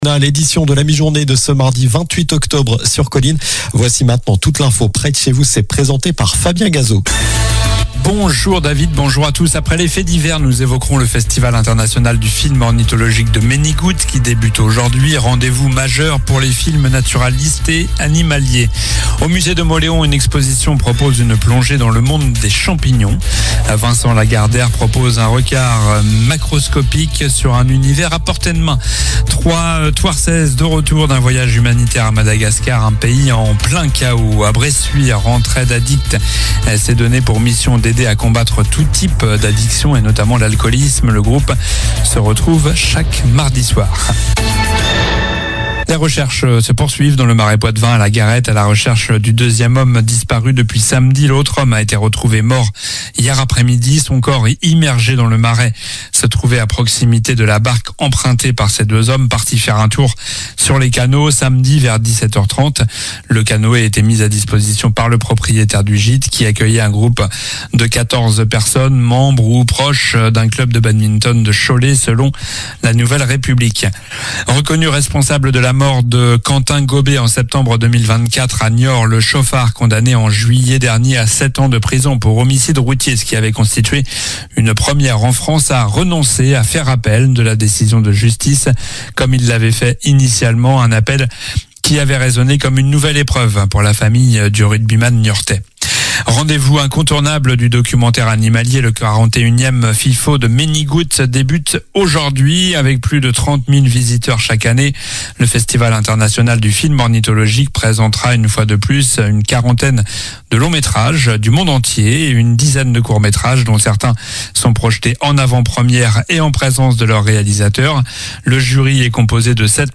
Journal du mardi 28 octobre (midi)